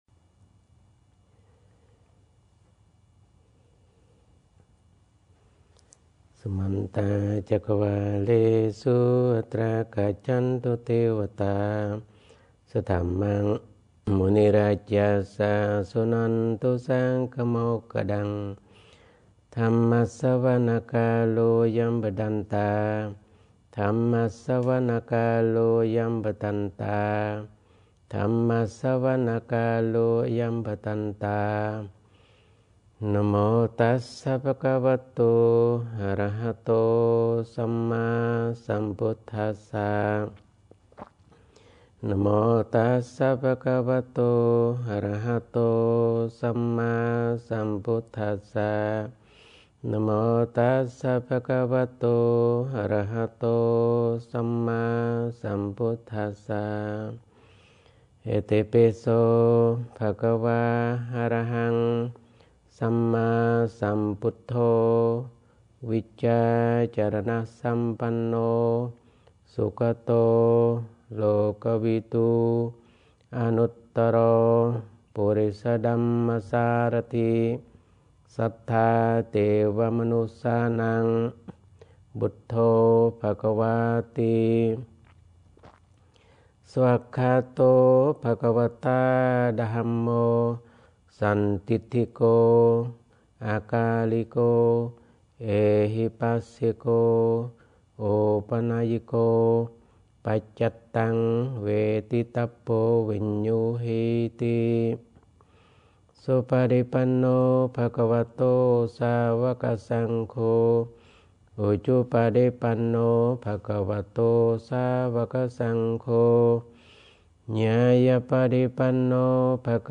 Morning Pali Chanting